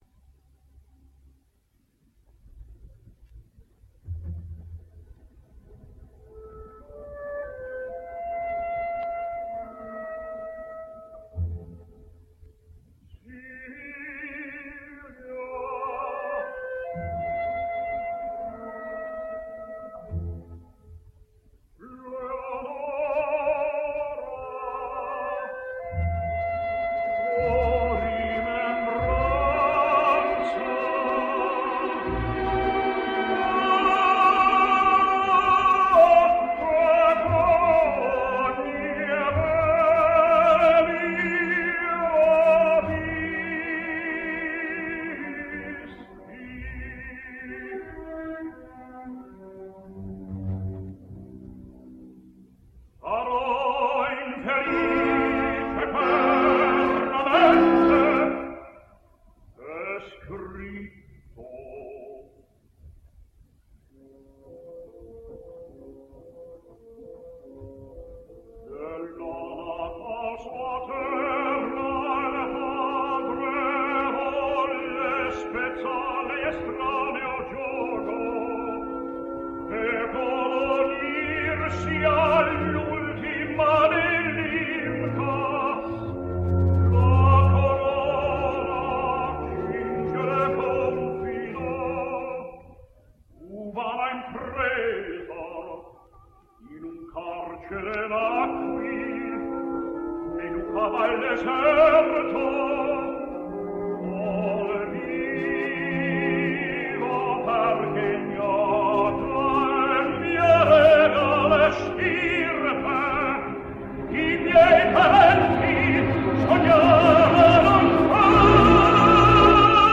American Tenor.
For our next record, we hear an Aria from Verde’s, un Ballo Masquera, Di Tu Se Fedele.
Tucker’s singing was never noticeable for finesse.
But he had few peers, in the projection of Italian passions, or in verva, ease, evenness and vocal security.